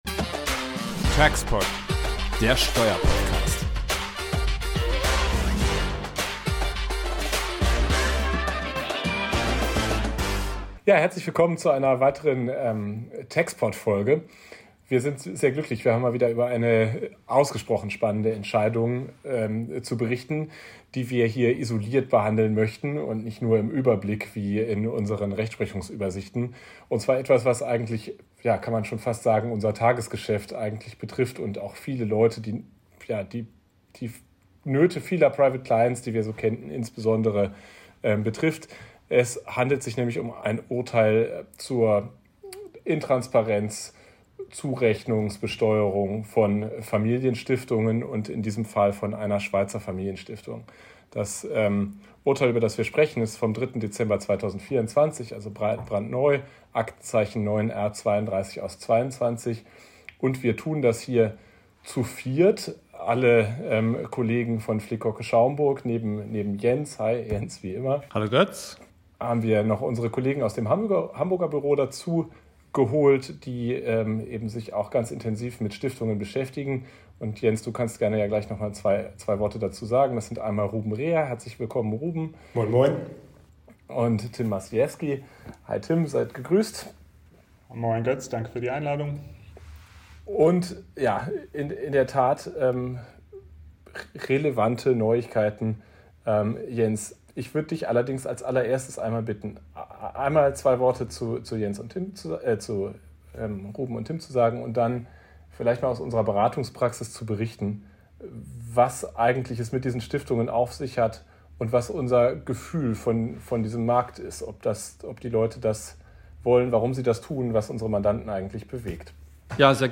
Anlass unserer digitalen Gesprächsrunde ist der in der Praxis seit Langem erwartete Entwurf des BMF-Schreibens zum Betriebsstättenbegriff vom 13. Februar 2026.